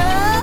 UHHH UP.wav